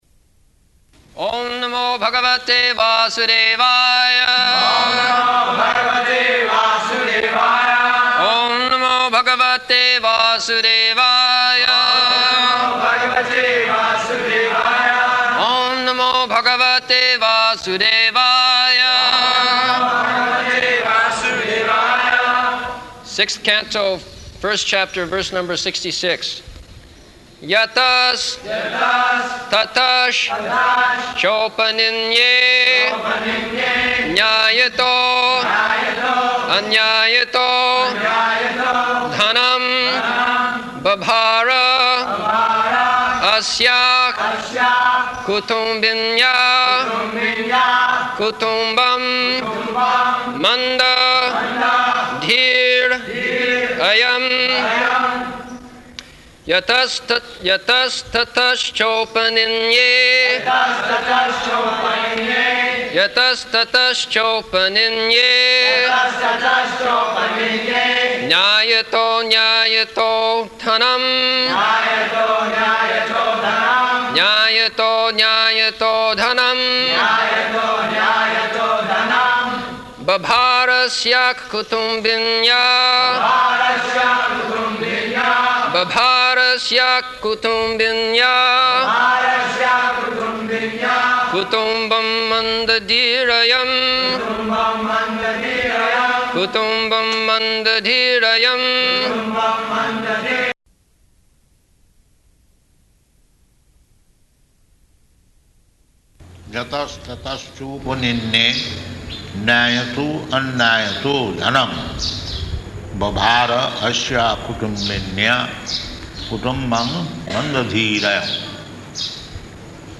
September 2nd 1975 Location: Vṛndāvana Audio file
[devotees repeat] Sixth Canto, First Chapter, verse number 66.